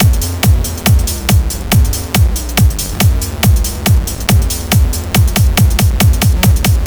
Tense Bass Boost Drum Loop 140 BPM
A drum pattern on top of some bass boosted... thing. It's not very loud at all but certainly sounds ominous and tense.
tensebassboostdrums_0.ogg